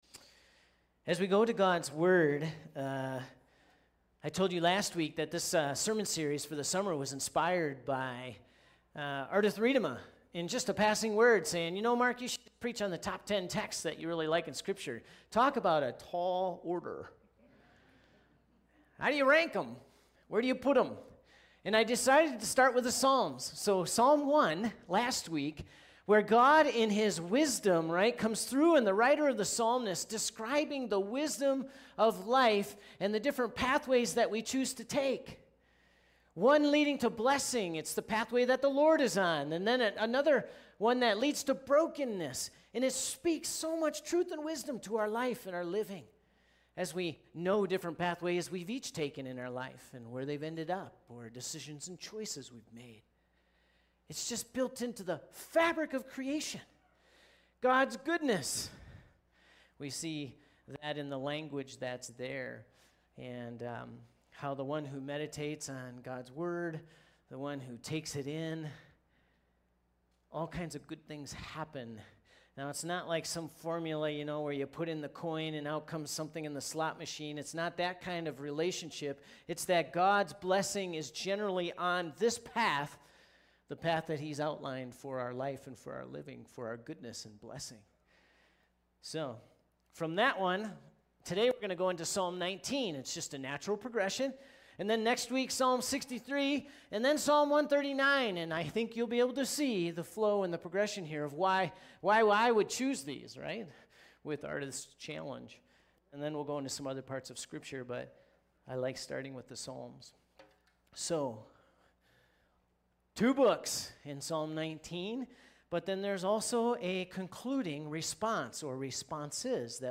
All You Need To Read Summer message series has begun, as we move from Psalm 1 (last week) to Psalm 19 (this week) – an easy transition!